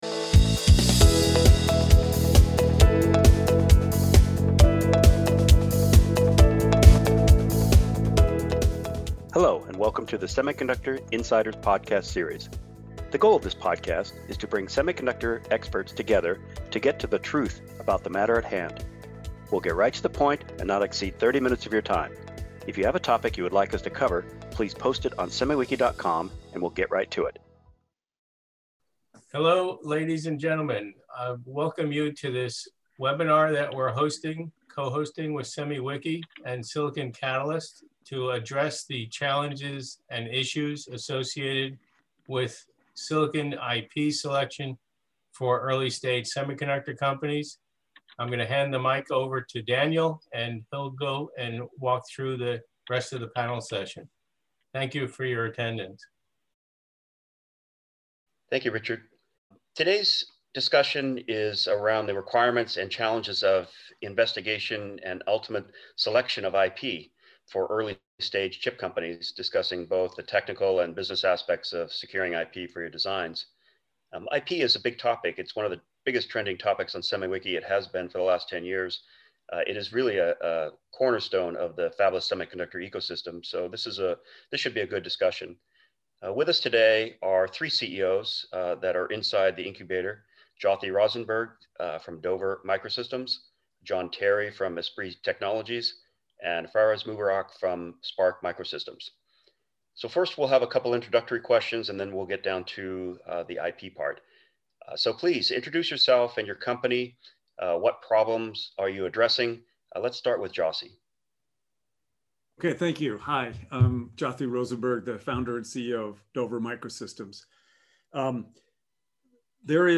A discussion around the requirements and challenges of investigation and ultimate selection of IP for early-stage chip companies, discussing both the technical and business aspects of securing IP for your designs.